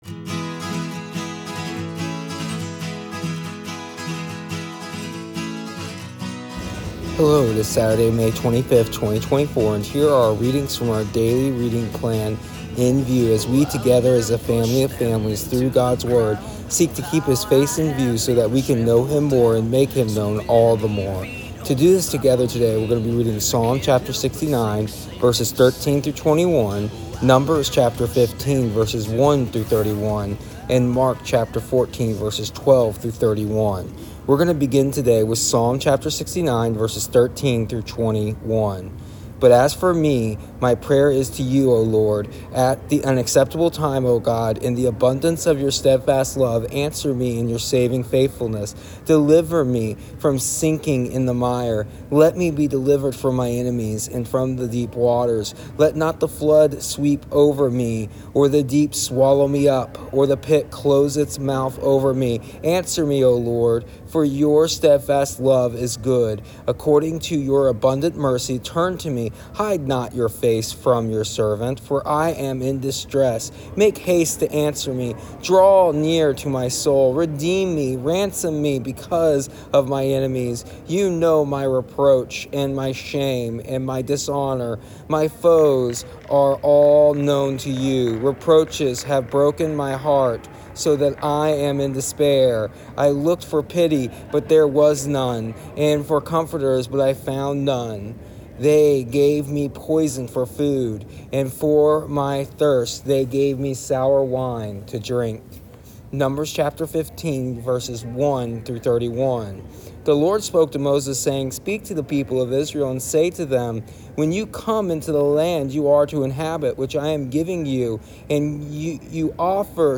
Here is the audio version of our daily readings from our daily reading plan “Keeping His Face in View” for May 25th, 2024.